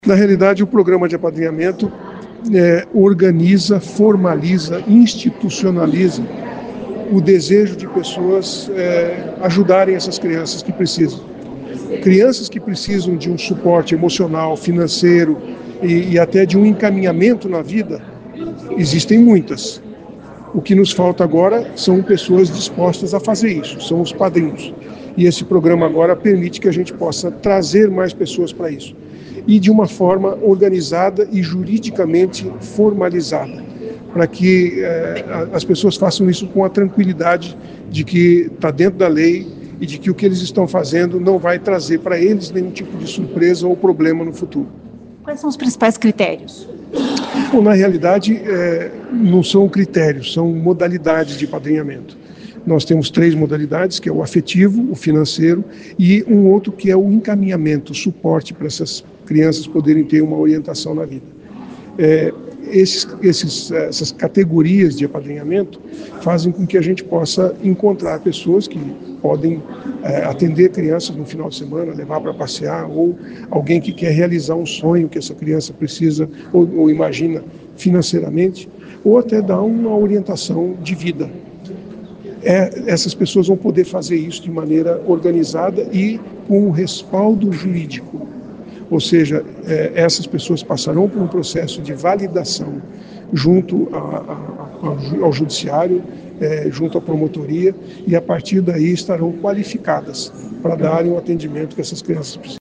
Ouça o que diz o prefeito Silvio Barros: